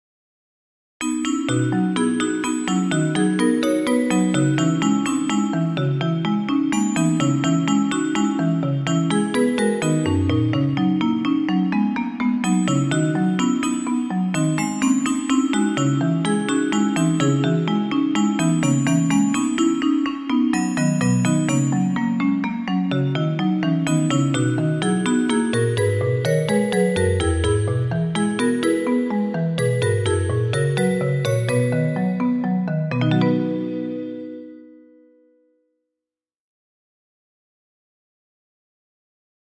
授業終了時チャイム
原曲とは異なる3拍子を用いることで、より心地よく聞こえるように工夫しています。